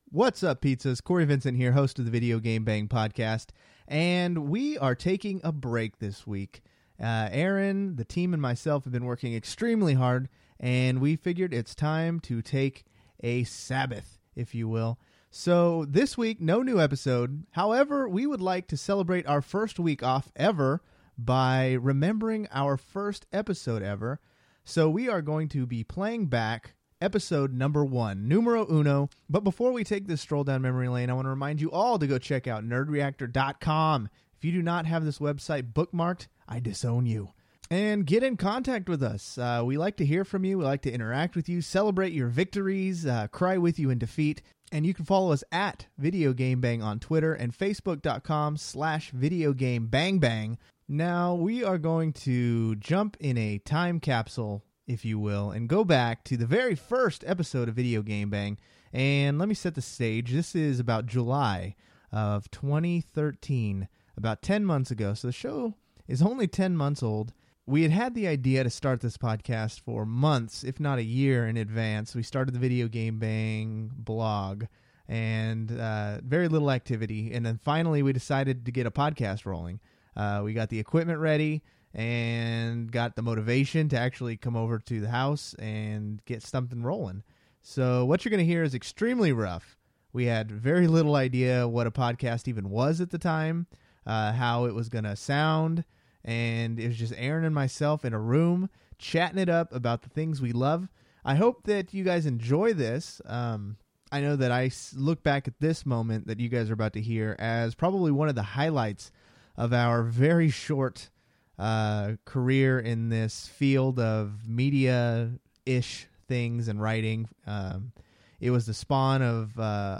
We set up a barbaric recording studio, locked the doors and this is what happened.